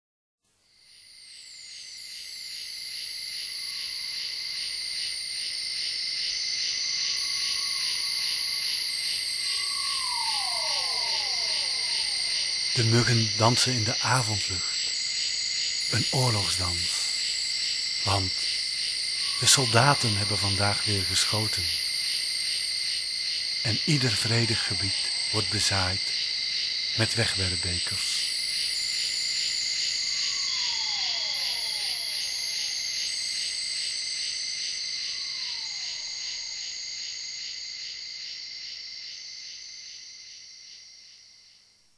Welkom bij de  prachtige Oerklankconcerten met klankschalen, stem en native instrumenten, ontdek en ervaar!
Betoverend , Mystiek, Romantisch . Krachtig , Wild en Teder.